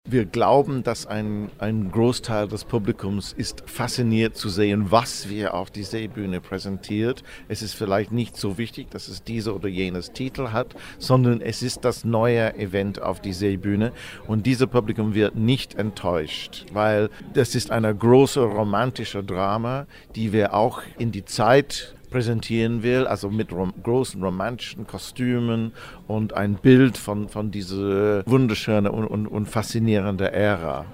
Bregenzer Festspiele Gesamtprogramm 2011 News